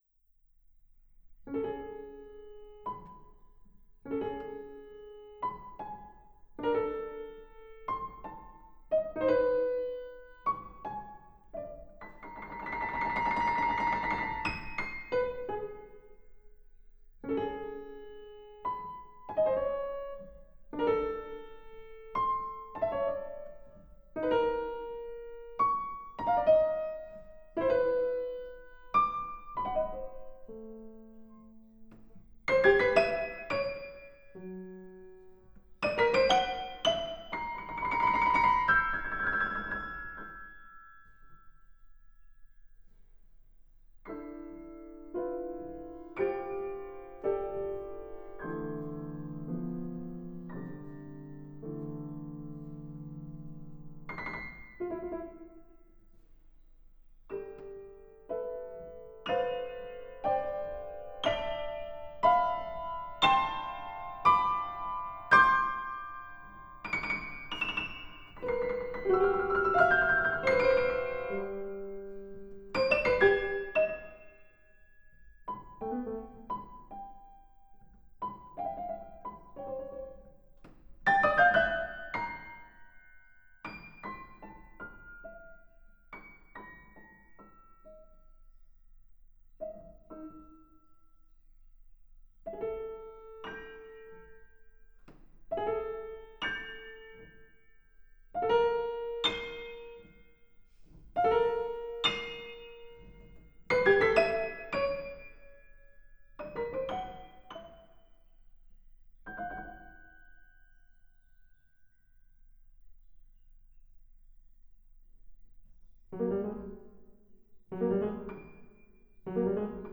for piano solo